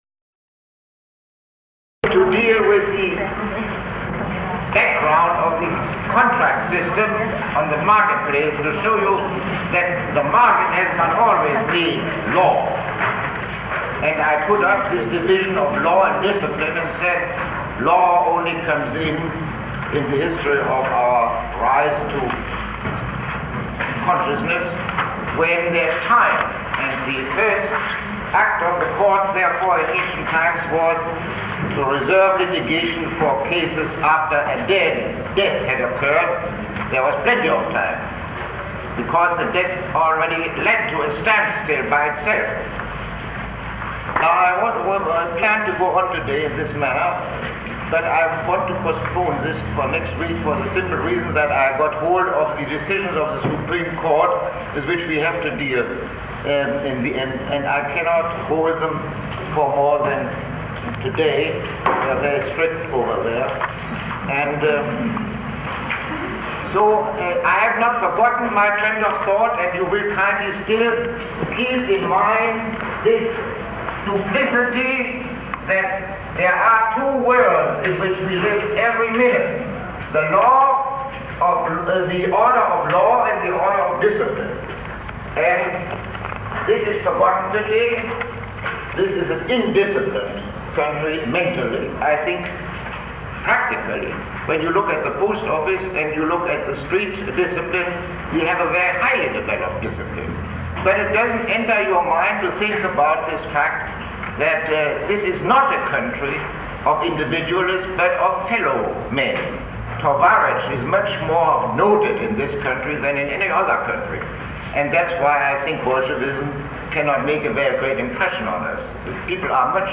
Lecture 39